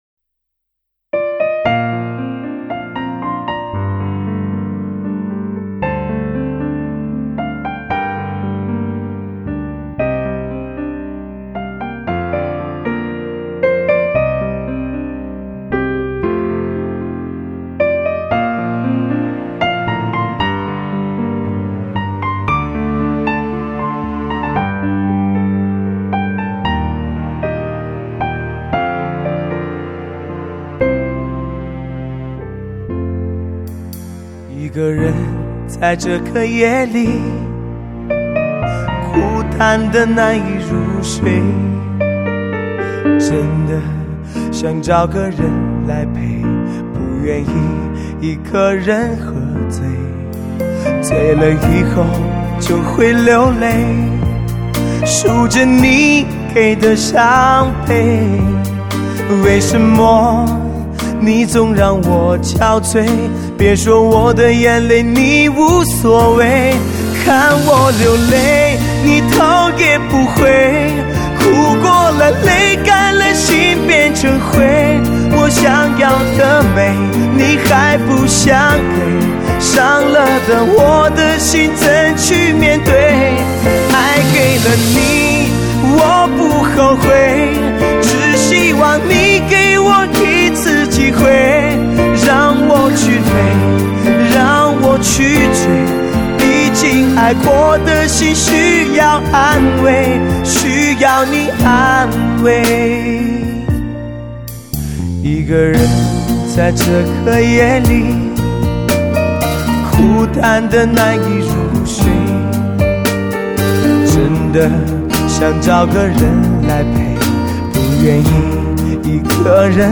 他们都是在各自的声音领域里最具代表性的歌唱家、歌手、组合。
加上精心的后期处理、绝佳的录音品质，绝对是您应该收藏的一张经典作品辑。